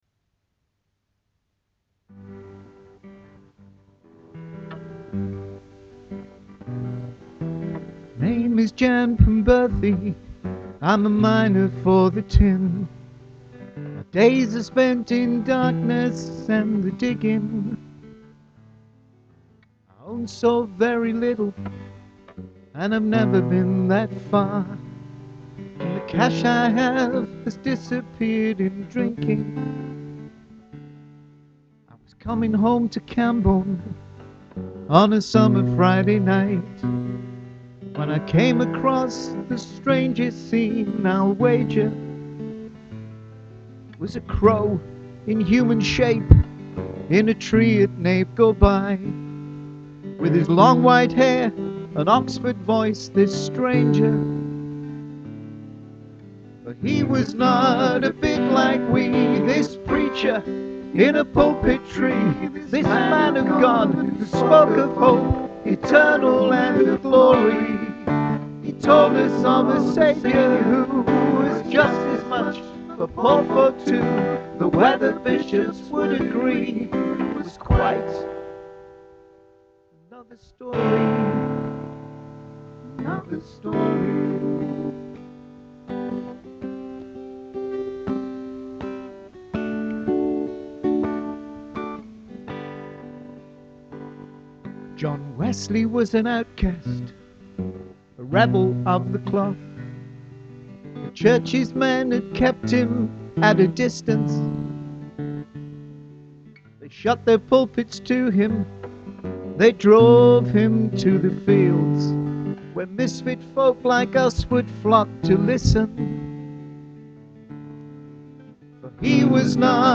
The song is called Jan Penberthy. The recording is pretty rough but gives you an idea of how it sounds.
It's a folk song about a Cornish miner who meets John Wesley during one of his outdoor preaching events.